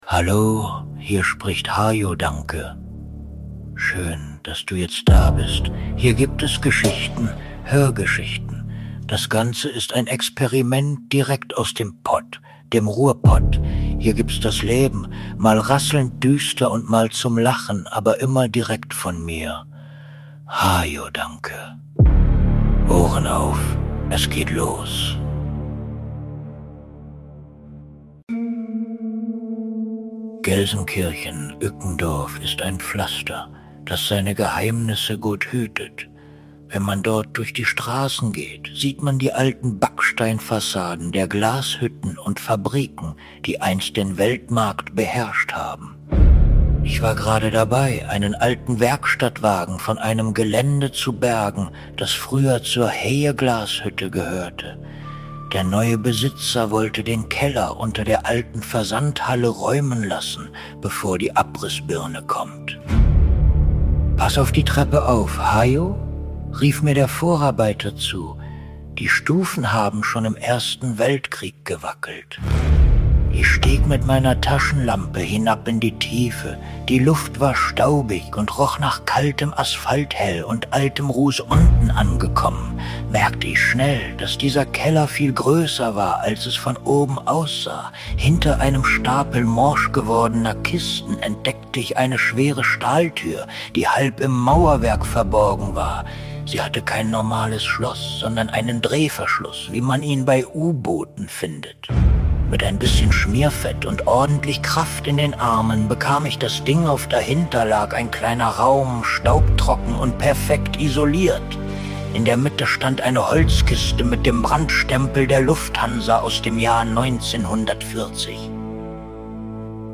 Hajo Dancke Hörgeschichte: Das Erbe der Schwingen! Ein brisanter Fund in der alten Glashütte Ückendorf führt Hajo auf die Spur eines dunklen Luftfahrt-Geheimnis